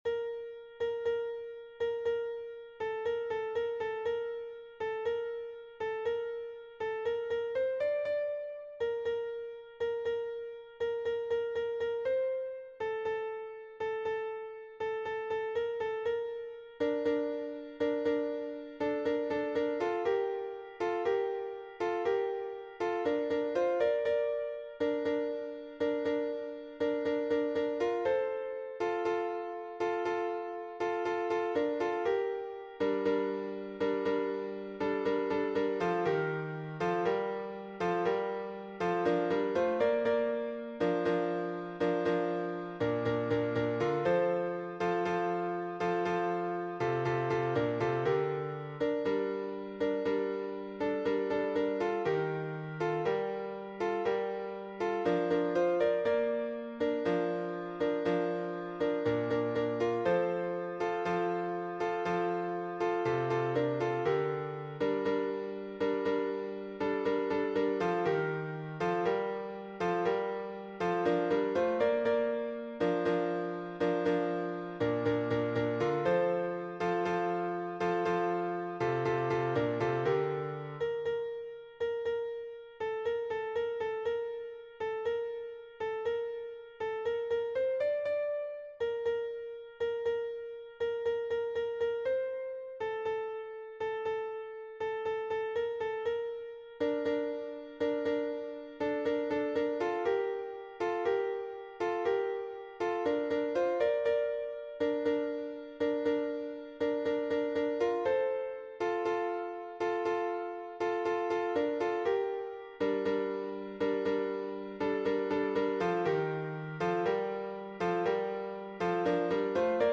MP3 version piano
Tutti